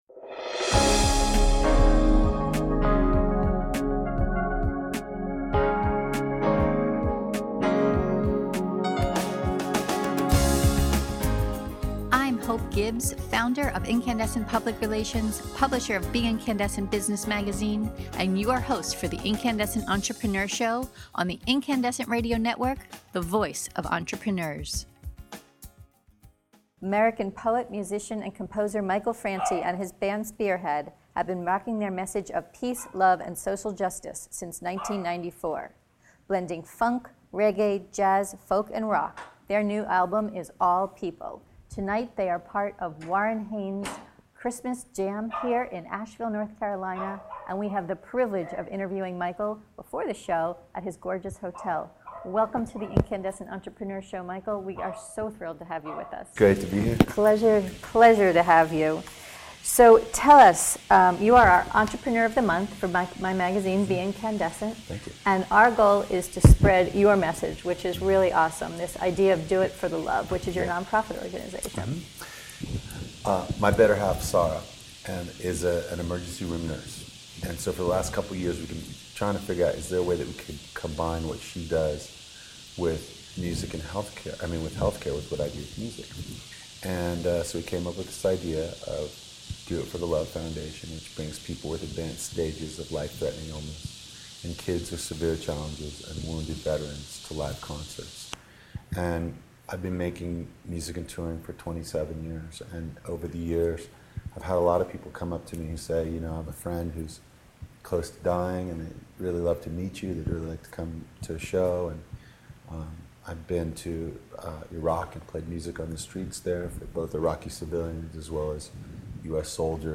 Check out our Q&A with rock star Michael Franti — the American poet, musician, and composer who with his band Spearhead spreads the message of love, peace, and social justice.